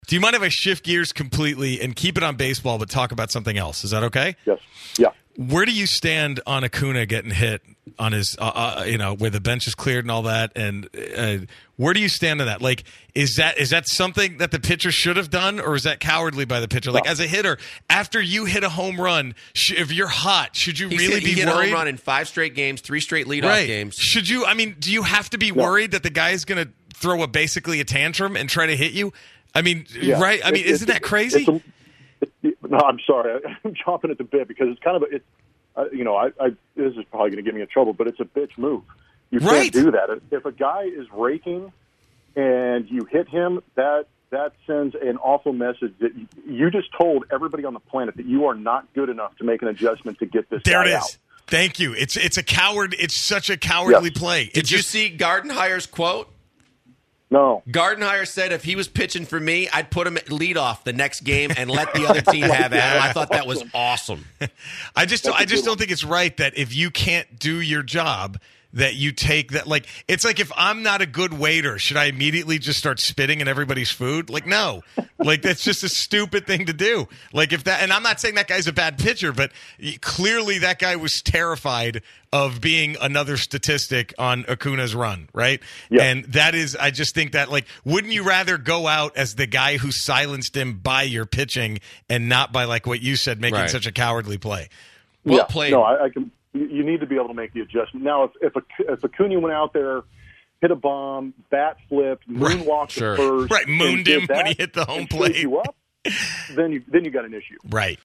8/17/2018 The Usual Suspects - Geoff Blum Interview